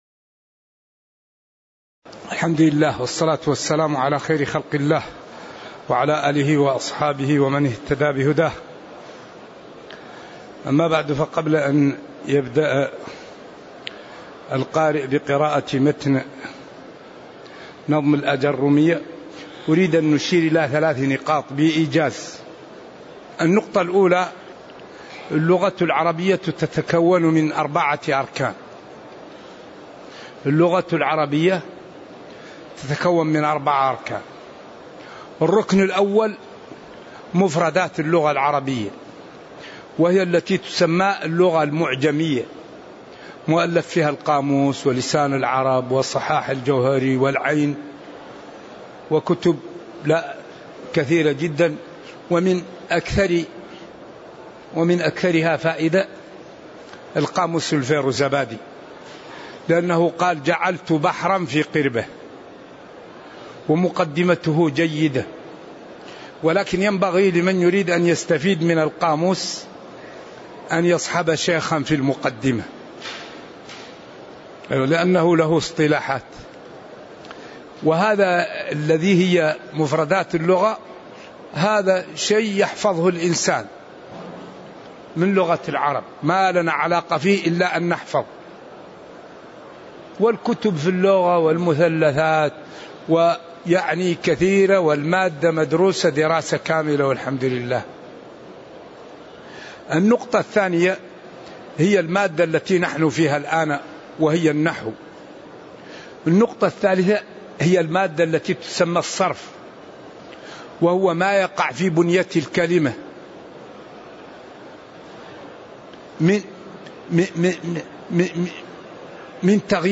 تاريخ النشر ١٢ محرم ١٤٤٥ هـ المكان: المسجد النبوي الشيخ